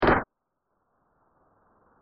mnl-medium noise 1.mp3